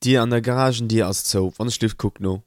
garagedoor_closed.mp3